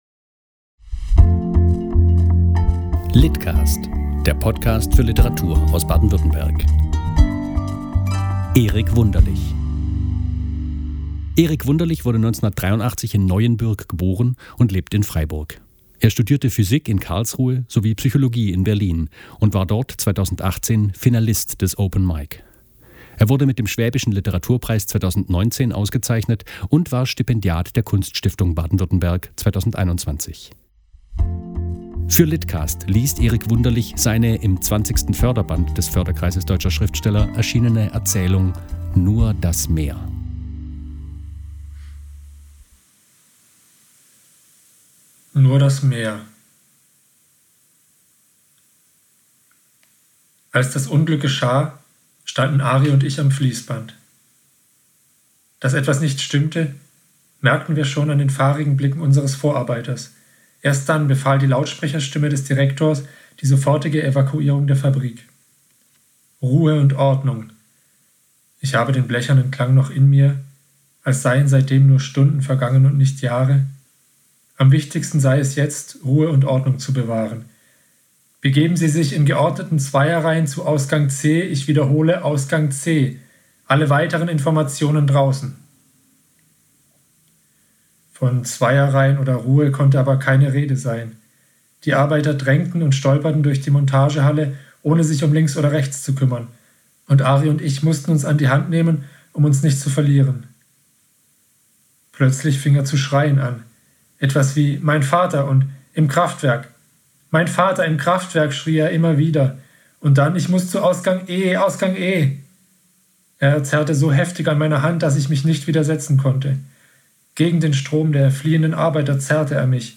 Förderband des Förderkreises deutscher Schriftsteller erschienene Erzählung "Nur das Meer" Mehr